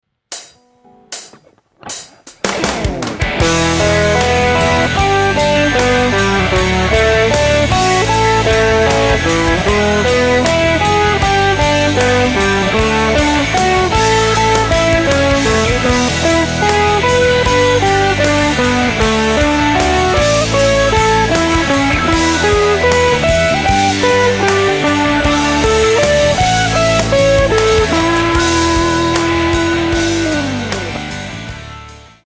In this guitar lesson the CAGED Chord System will be used over a 4 chord progression showing ideas to create a guitar solo based on arpeggios.
The chords in the example are E G D and A major which are the same chords used in the chorus of Alive by Pearl Jam and countless other songs.
The final exercise mixes all the shapes from the CAGED chord system giving you an idea of how they can be combined.
CAGED Chord Shape Arpeggios